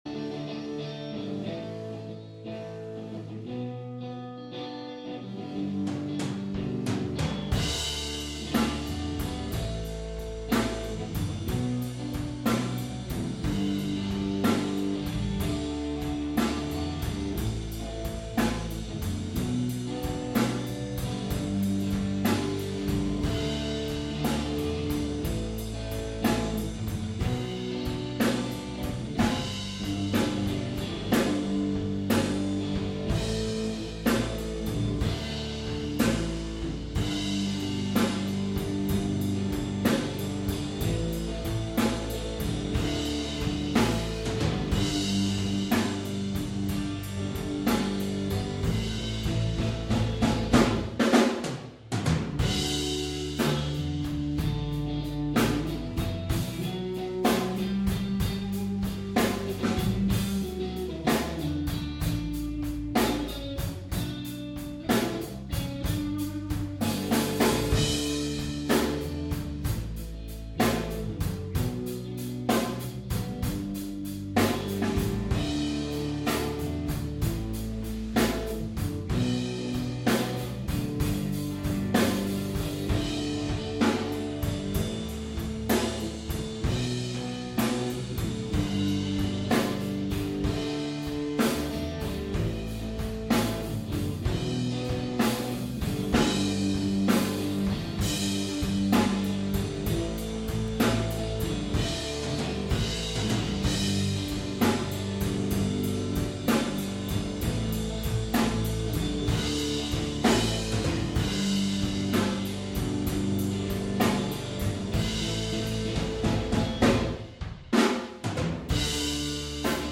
I thought I saw you No Vocal